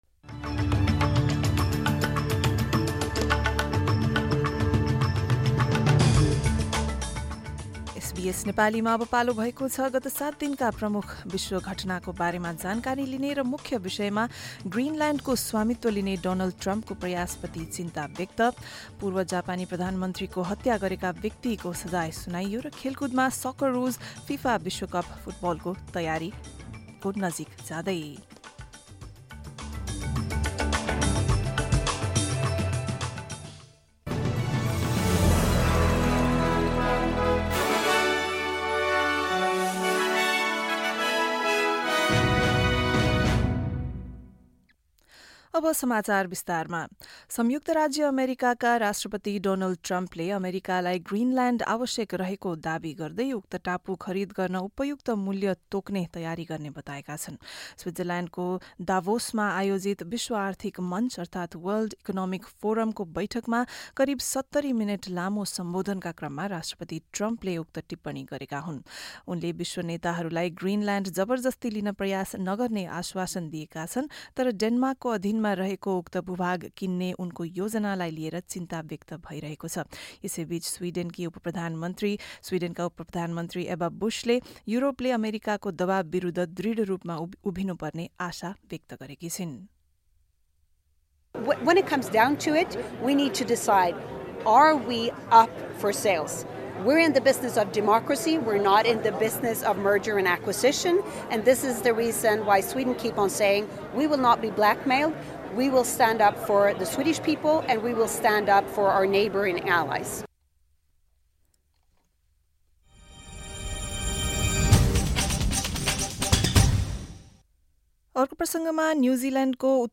गत सात दिनका प्रमुख विश्व समाचार